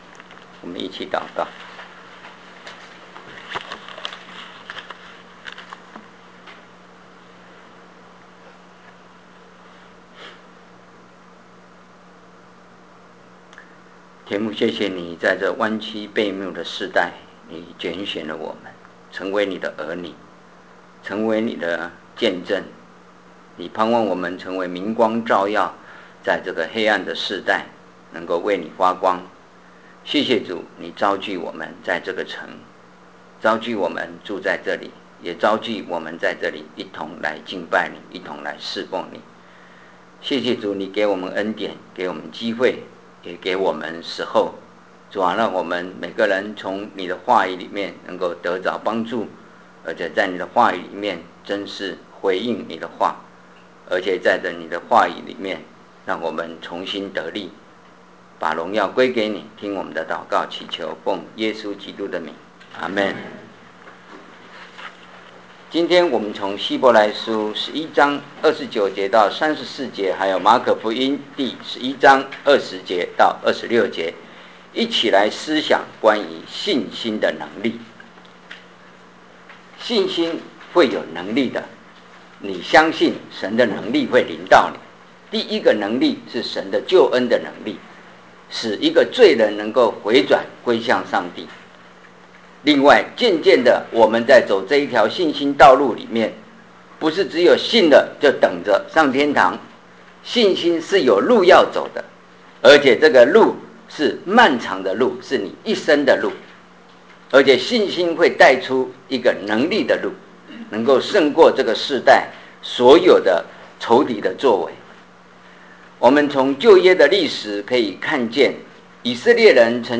信心的力量 - 三城华人教会